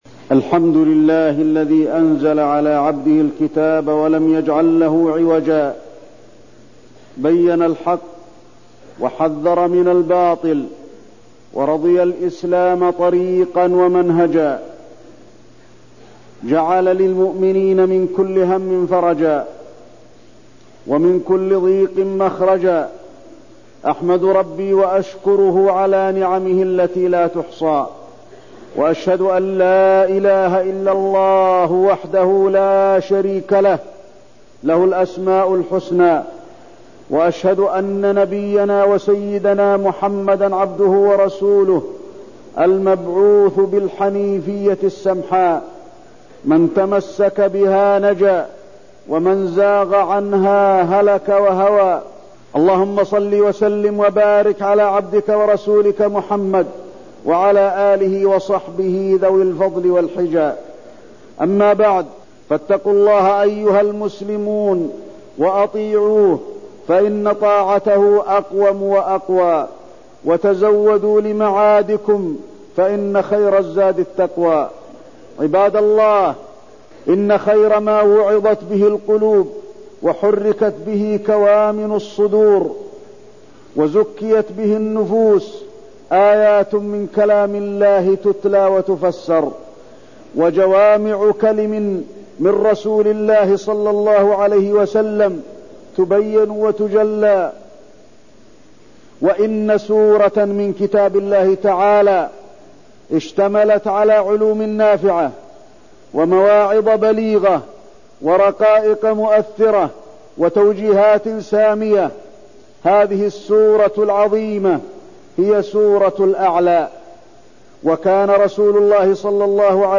تاريخ النشر ٩ ذو القعدة ١٤١٣ هـ المكان: المسجد النبوي الشيخ: فضيلة الشيخ د. علي بن عبدالرحمن الحذيفي فضيلة الشيخ د. علي بن عبدالرحمن الحذيفي تفسير سورة الأعلى